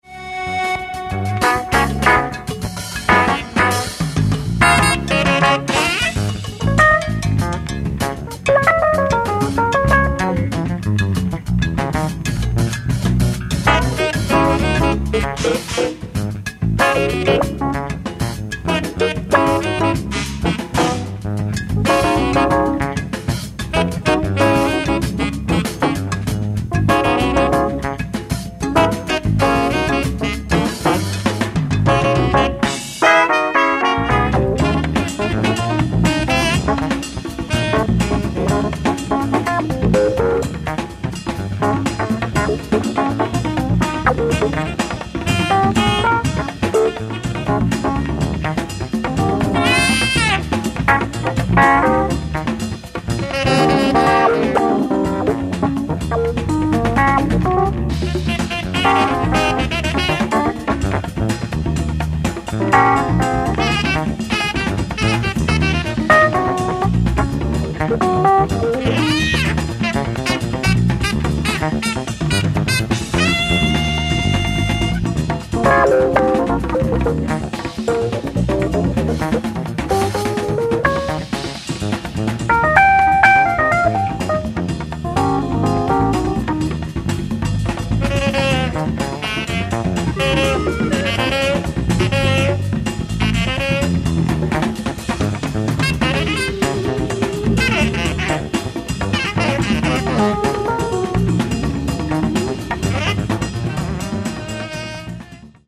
Live At City Center, New York, NY 06/30/1976
SOUNDBOARD RECORDING